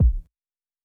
Kick WUSYANAME.wav